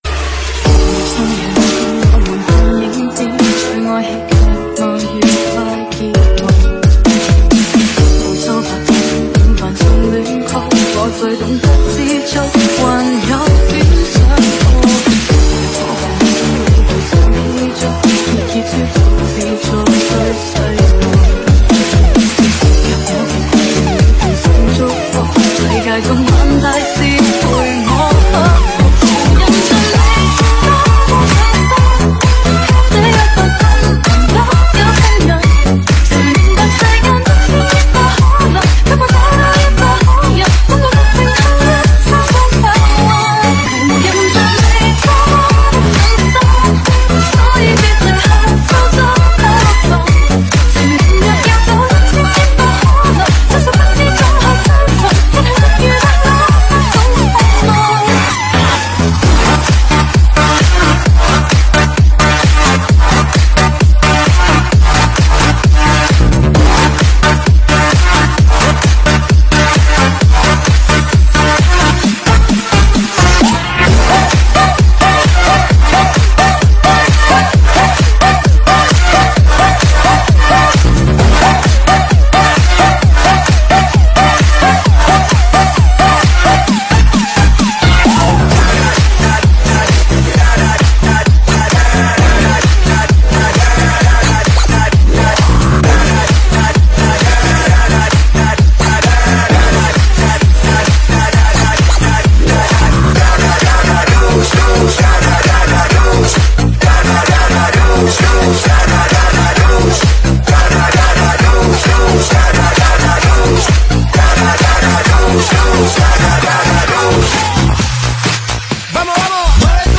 本铃声大小为752.8KB，总时长122秒，属于DJ分类。